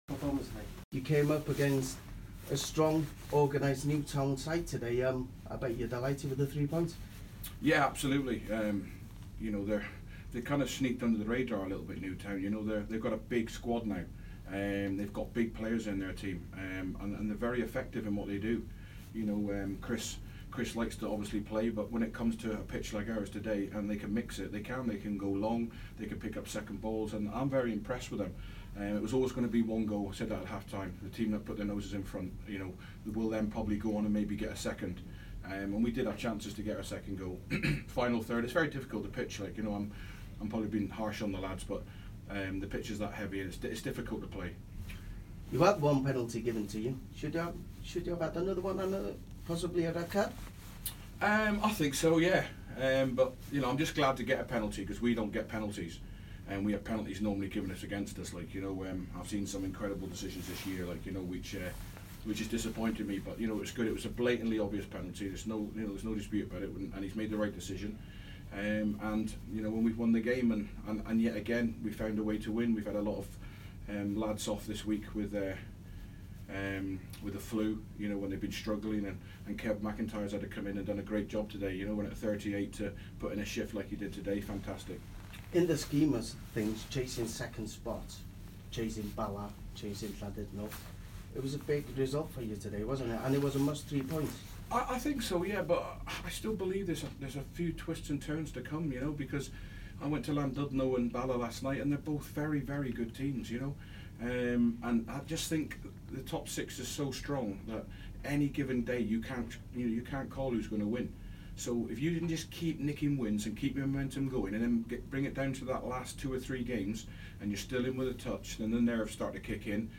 Sgorio interview